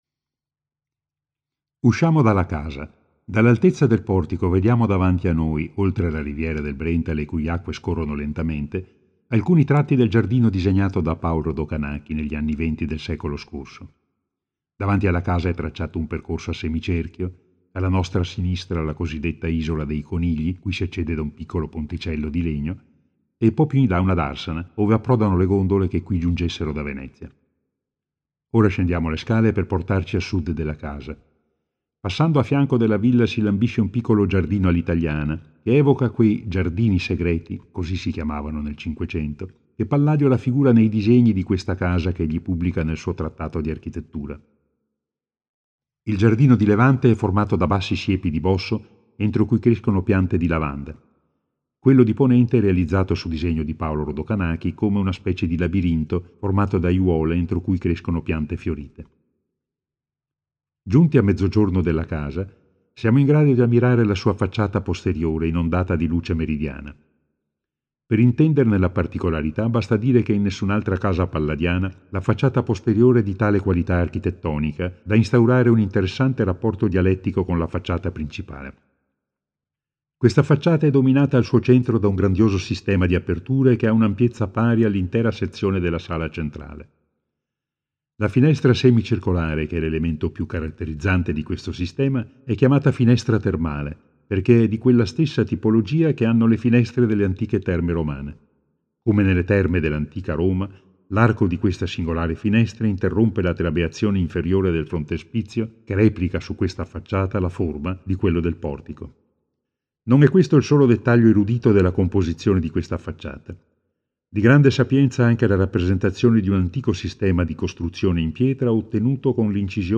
Audioguida (3:34)